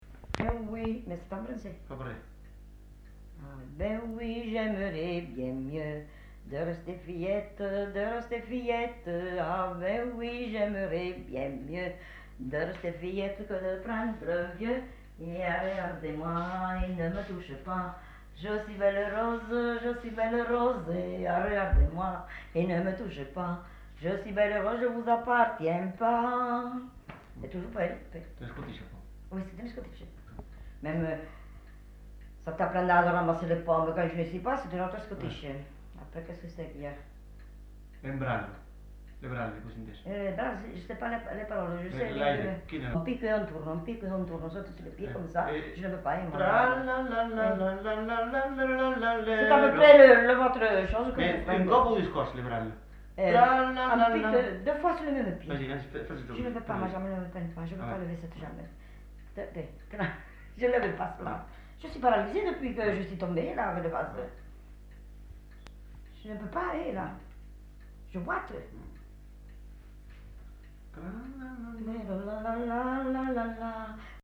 Aire culturelle : Lauragais
Genre : chant
Type de voix : voix de femme
Production du son : chanté
Danse : scottish